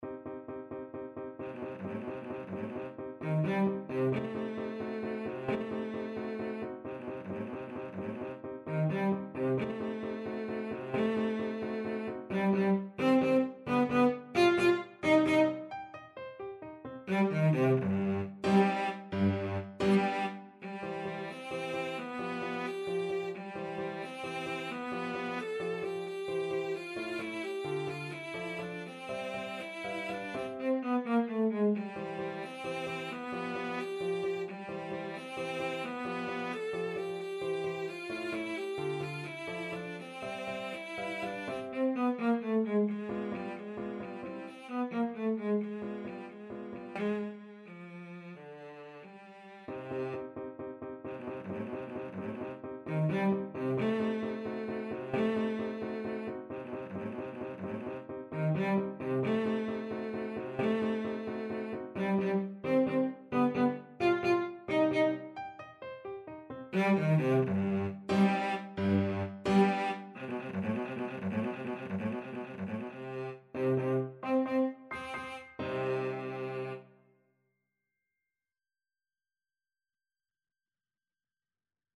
Cello
C minor (Sounding Pitch) (View more C minor Music for Cello )
3/8 (View more 3/8 Music)
Allegro con brio (.=104) .=88 (View more music marked Allegro)
Classical (View more Classical Cello Music)
burgmuller_op100_15_VLC.mp3